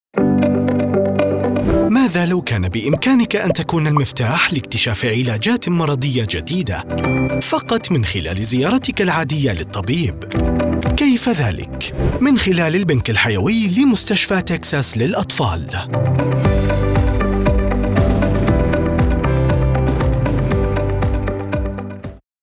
Voix
Commerciale, Profonde, Naturelle, Fiable, Corporative
E-learning